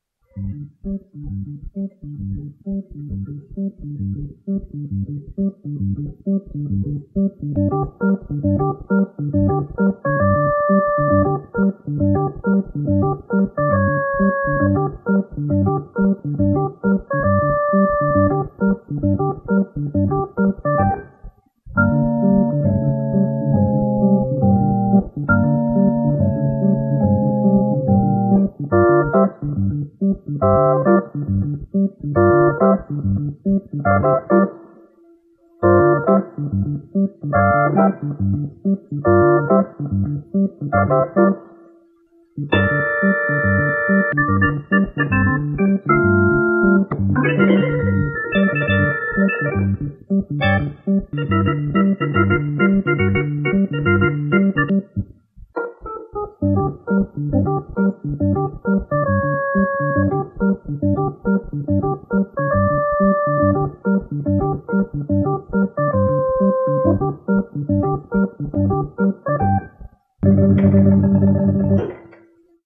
Je me suis donc finalement décidé à enregistrer le X-5.
Donc 2 petites impros prises sur le vif - soyez indulgents.
La 1ère, le micro (petit machin) est posé sur la Leslie, je sais, c'est pas malin et la 2ème, sur le côté.
Le 2e pourrait servir de générique à une série policière, c'est vrai ! 8) Le X5 y sonne pas mal du tout, et les percus sont bien meilleures que celles du B200, dans mon souvenir en tous les cas.
Pour le 2e, ça sature un max dans mon système et surtout dans le grave, de sorte que je ne peux pas bien entendre.